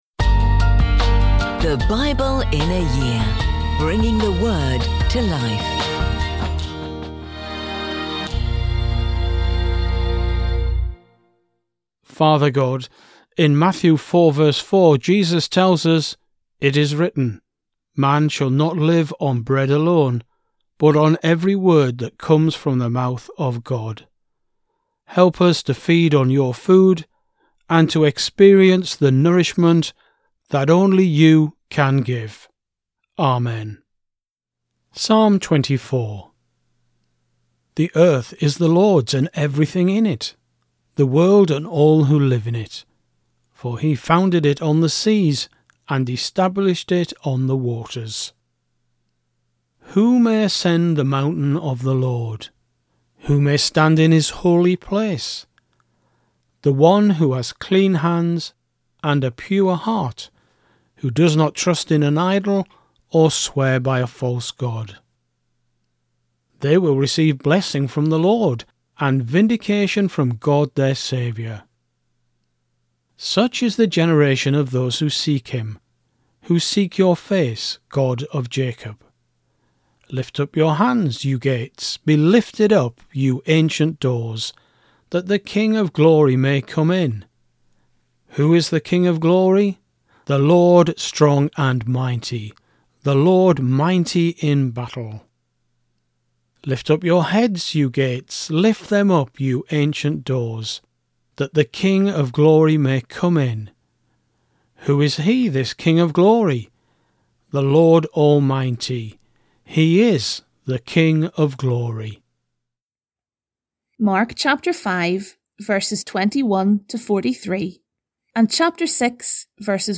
Every day throughout the year we'll be bringing you an audio scripture reading from the Old and New Testament.